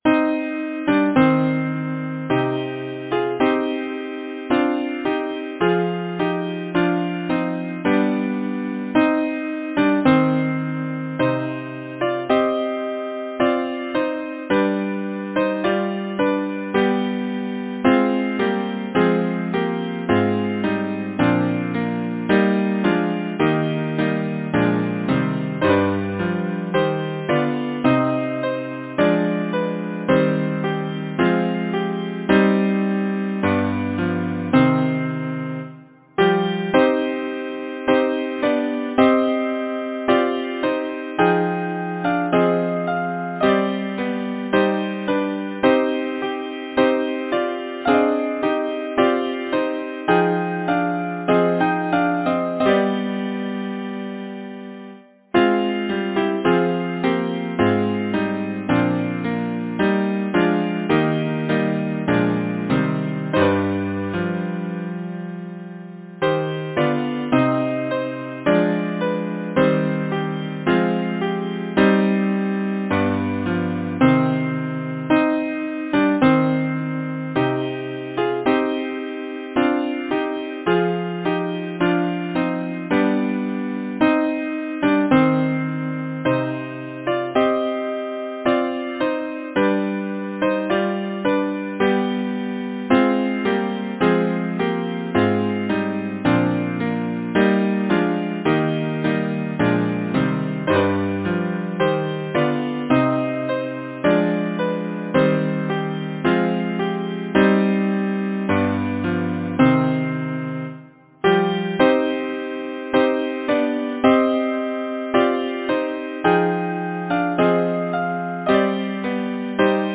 Title: Roslin Castle Composer: Henry A. Lambeth Lyricist: Richard Hewitt Number of voices: 4vv Voicing: SATB Genre: Secular, Partsong
Language: English Instruments: A cappella